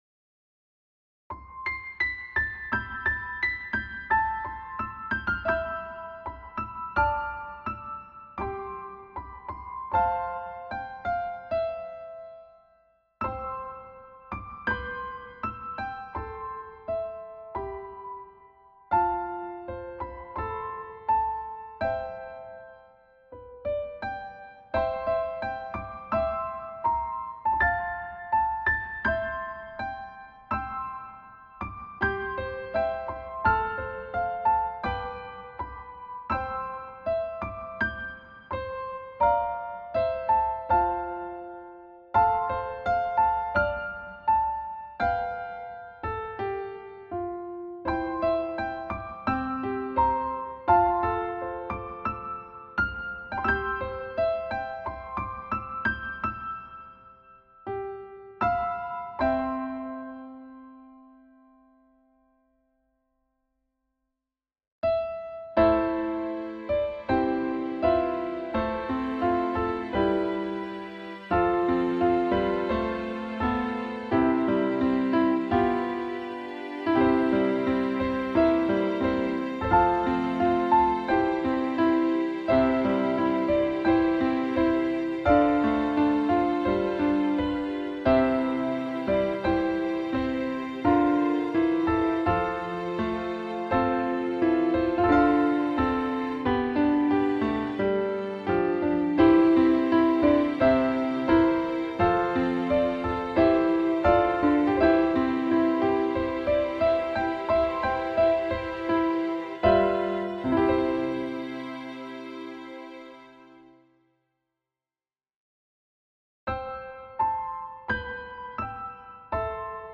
piano
He recorded on an Akai MPK 88 keyboard controller using Steinway and string ensemble samples from Logic Studio.